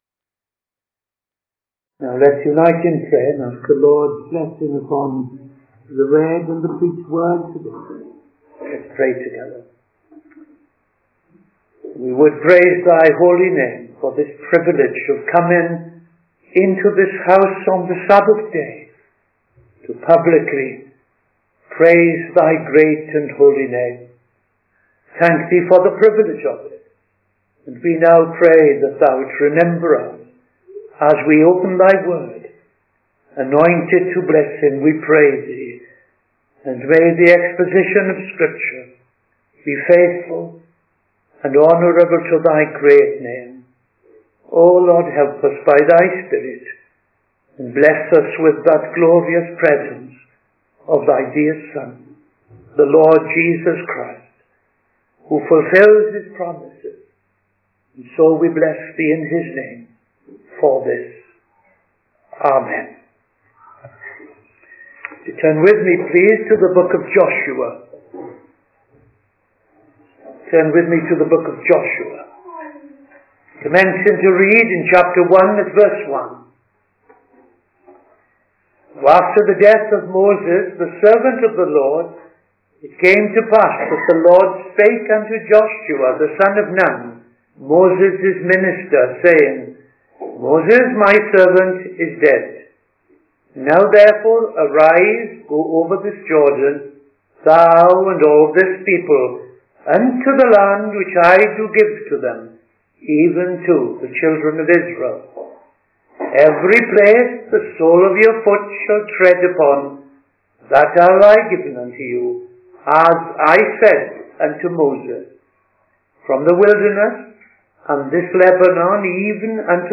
Midday Sermon 31st August 2025 Minister
Joshua Joshua Part 1 Dedicatory Prayer and Reading Joshua 1:1-9 Sermon Joshua Part 1 ‘Joshua’s calling and encouragement’ (1) Joshua 1:1-9 Closing Prayer